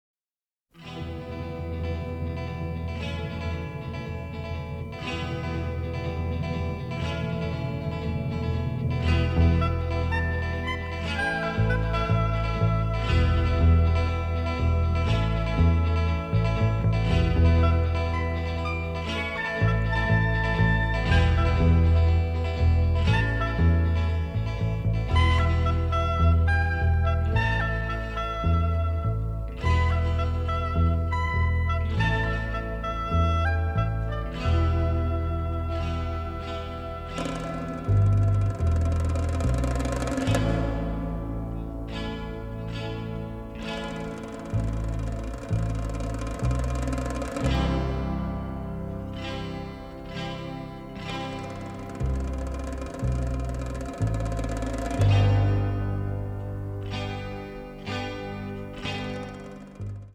western score